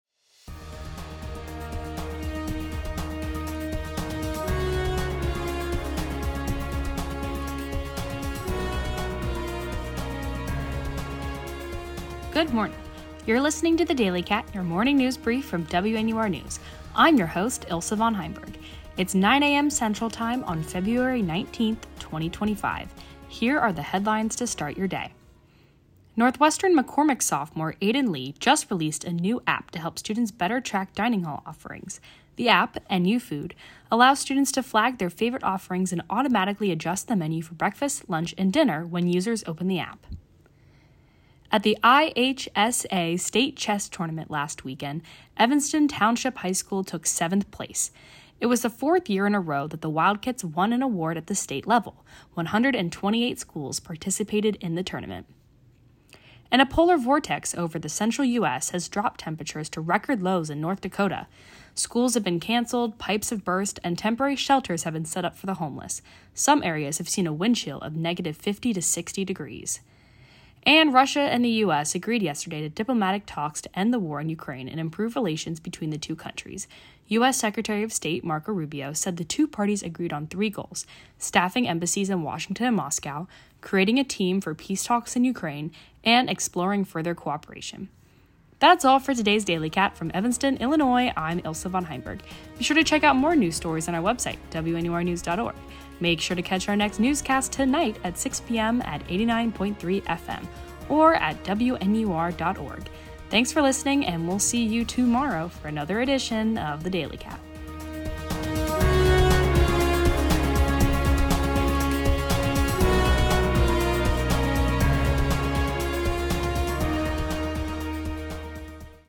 February 18, 2025: NUFood app, Northwestern dining, Ukraine, Russia, United States, Marco Rubio, Illinois state chess tournament, ETHS chess, polar vortex, North Dakota polar vortex. WNUR News broadcasts live at 6 pm CST on Mondays, Wednesdays, and Fridays on WNUR 89.3 FM.